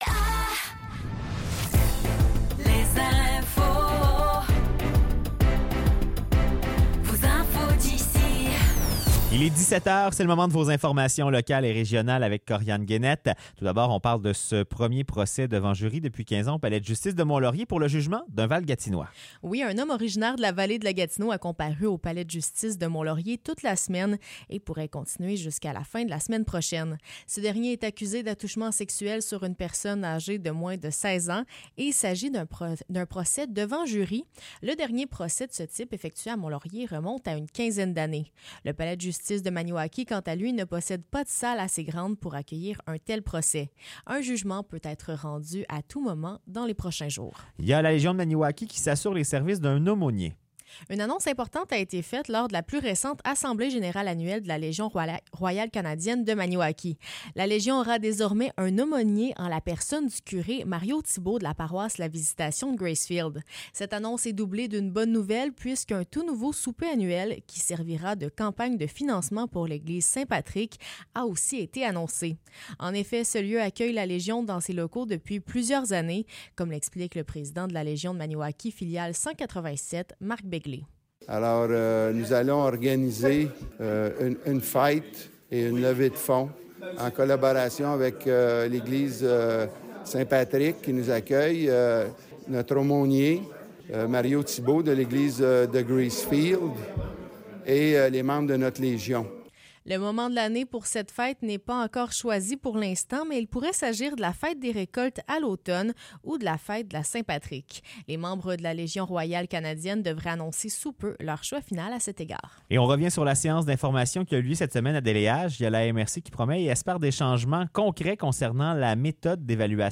Nouvelles locales - 11 avril 2024 - 17 h